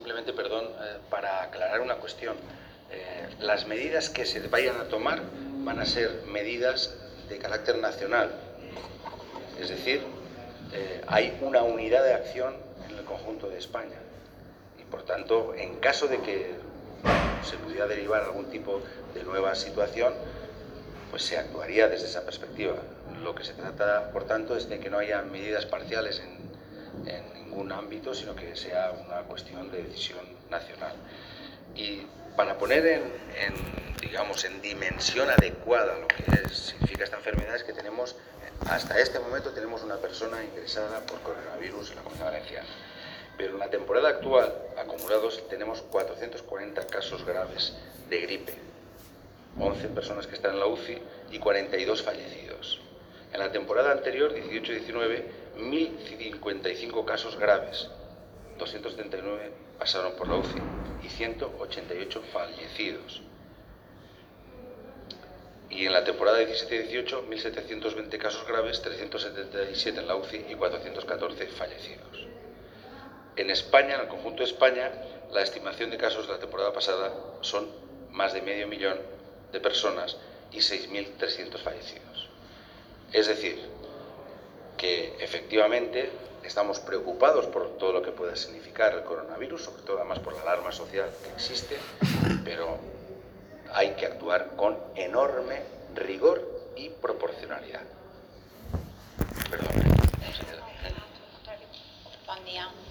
Diversos cortes de audio de las declaraciones de Ximo Puig en la mañana del día de hoy.